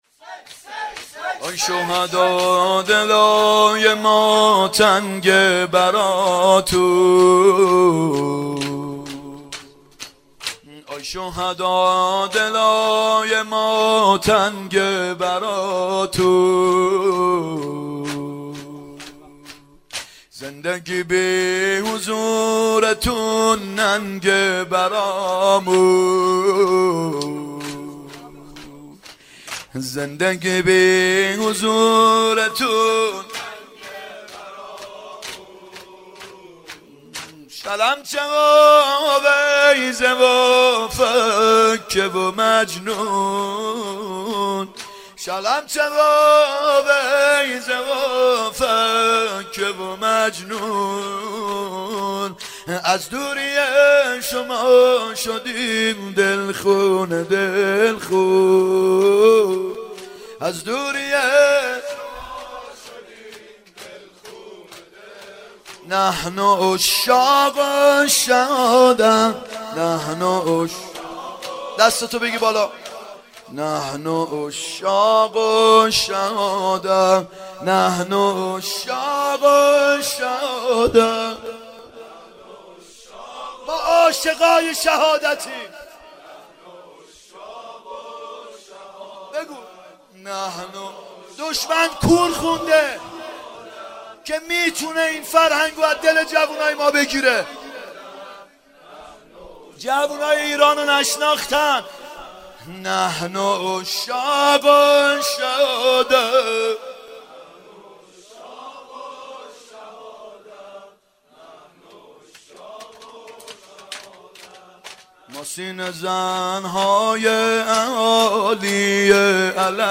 فاطمیه اول هیئت یامهدی (عج)